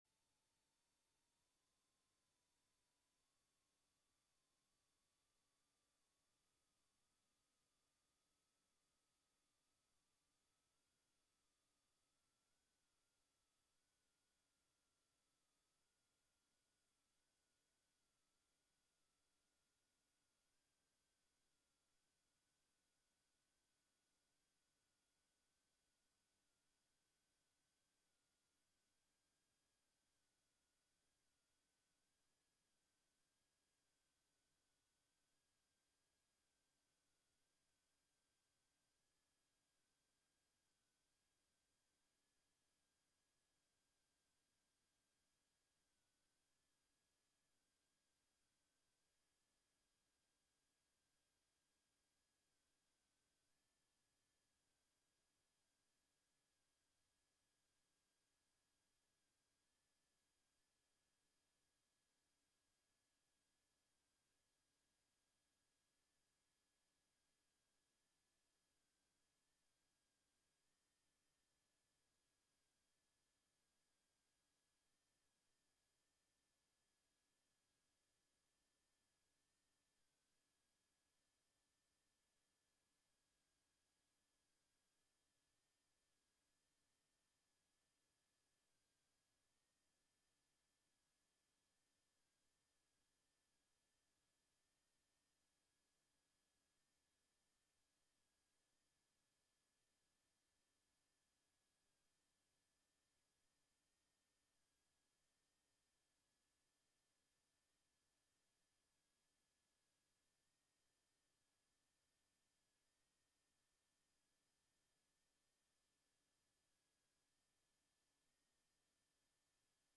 gallery opening performance